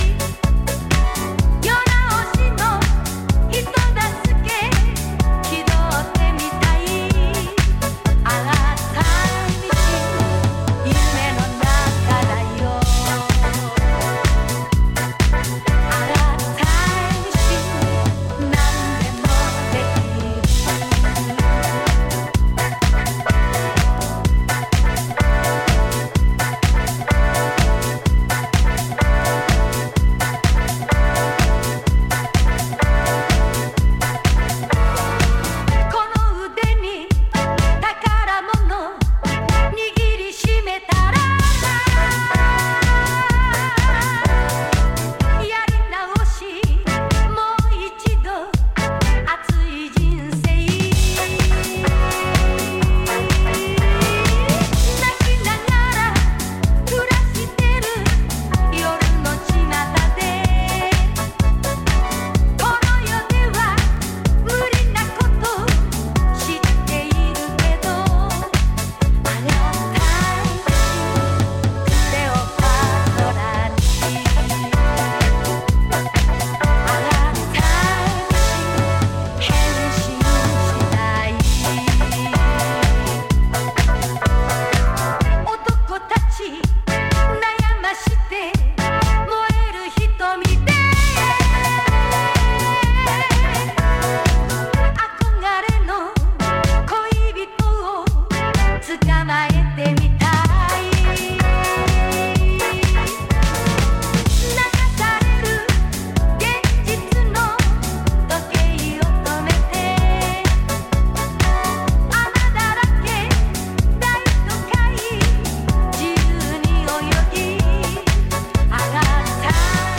例に漏れず、全曲DJユースで強力なリエディット集となっています。